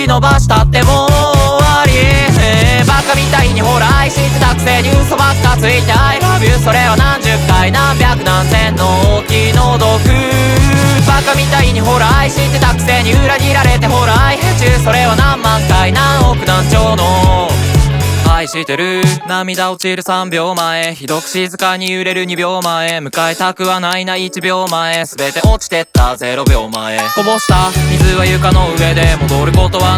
Жанр: J-pop / Поп